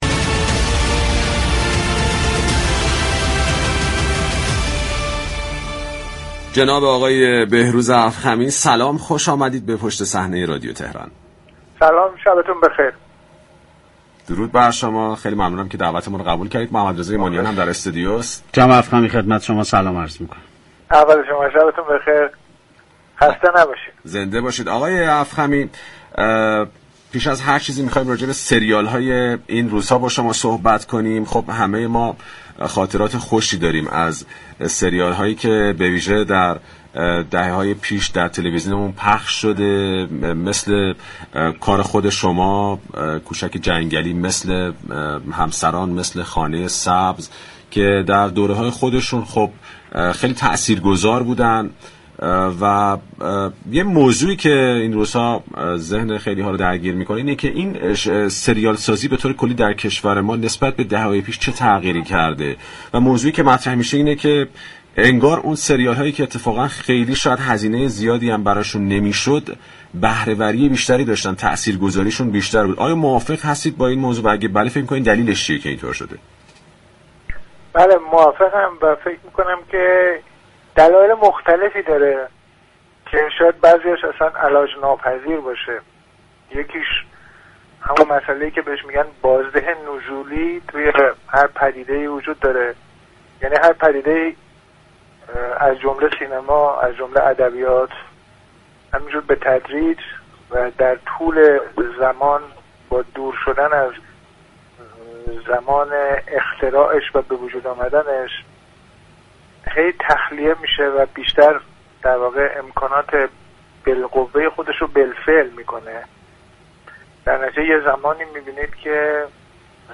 به گزارش پایگاه اطلاع رسانی رادیو تهران، بهروز افخمی، كارگردان مطرح كشورمان در گفتگو با برنامه پشت صحنه درباره ساخت سریال هایی مثل میرزا كوچك خان جنگلی، همسران و خانه سبز كه در دوره خود بسیار تاثیرگذار بودند و تفاوت بهره وری آنها با سریال هایی كه در حال حاضر ساخته می شوند، هزینه ساخت زیادی برای تولید دارند و از تاثیرگذاری بالایی هم برخوردار نیستند گفت: دلایل مختلفی وجود داشته باشد كه بعضی‌هایش علاج ناپذیر است.